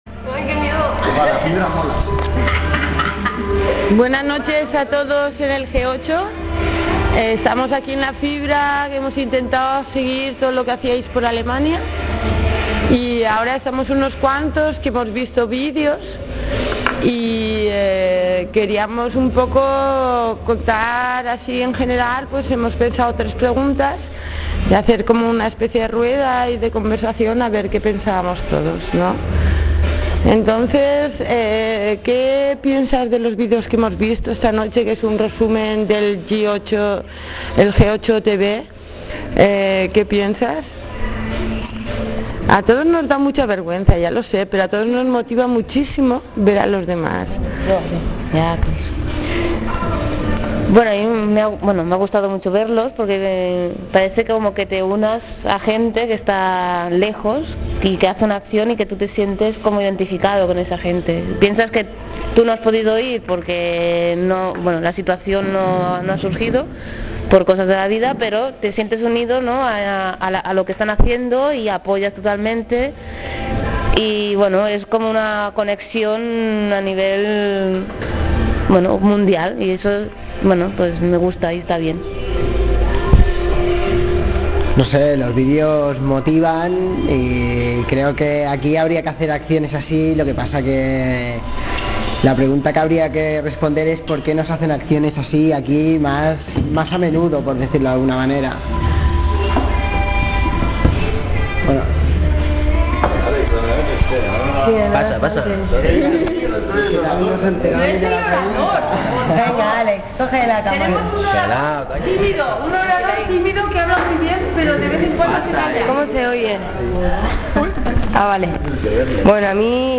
Audio contra el G8 : Mataró infopoint 3 días de información contra el G8 en el infopoint de Mataró. Un audio en español: la última noche donde se grabaron tres preguntas y se hizo una rueda de grabación entre los asistentes que quisieron participar.